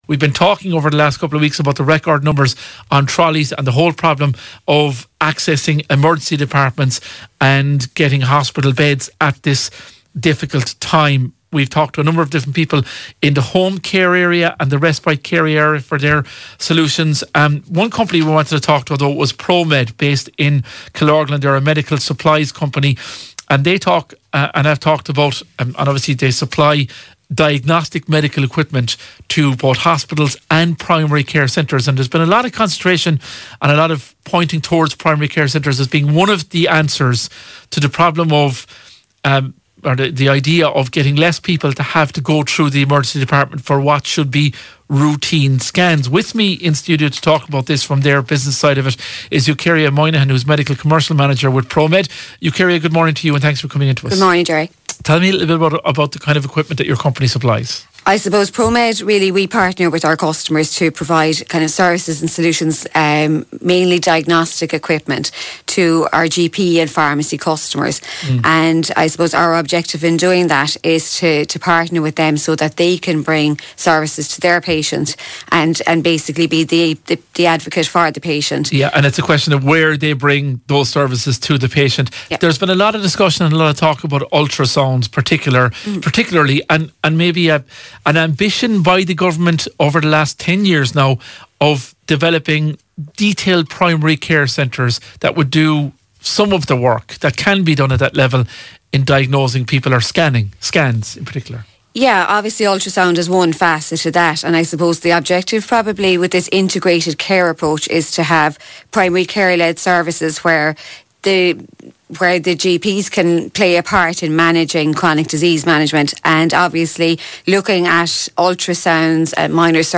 Radio Kerry Interview